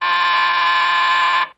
Door Buzzer, Low Pitched.